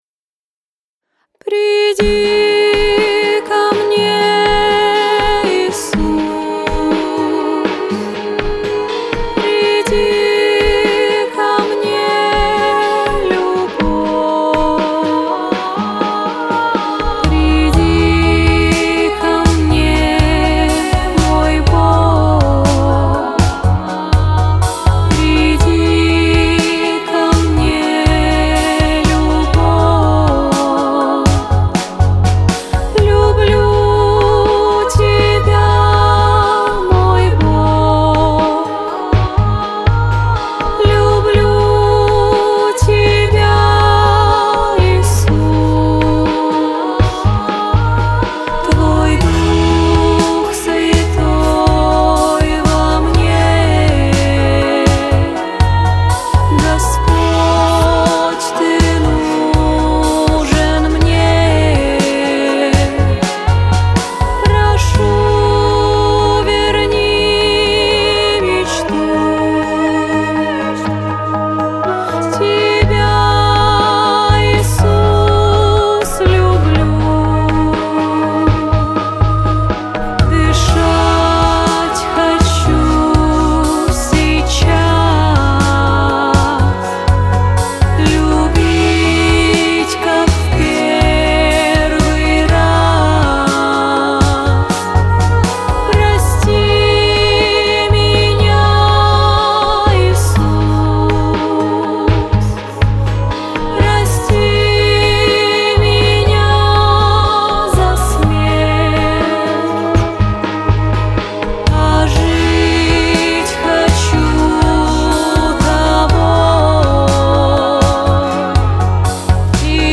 328 просмотров 322 прослушивания 22 скачивания BPM: 52